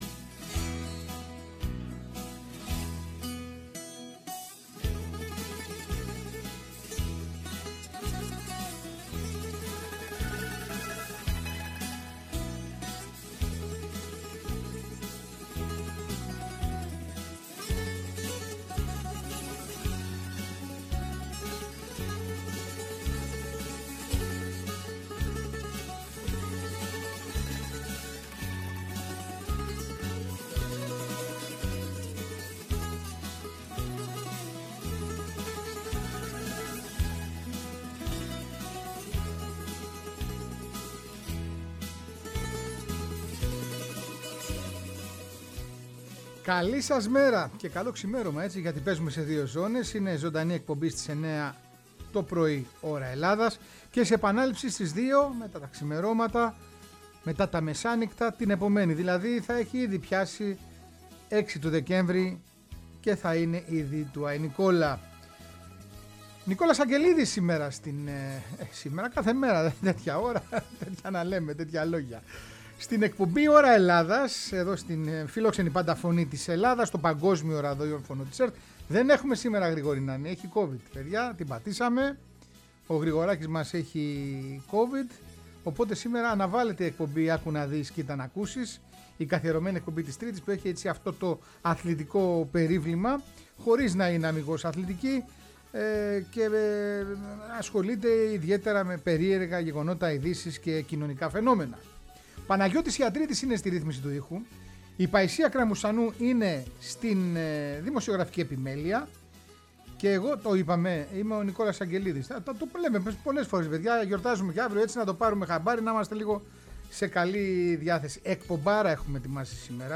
Η ΦΩΝΗ ΤΗΣ ΕΛΛΑΔΑΣ Ωρα Ελλαδας Αθλητισμός Ντοκιμαντέρ ΝΤΟΚΙΜΑΝΤΕΡ 30ο Συνταγμα ΕΛΑΣ Αντισταση ΕΛΑΣ ΚΑΤΟΧΗ ΠΑΟΚ Πελλα ΦΩΝΗ ΤΗΣ ΕΛΛΑΔΑΣ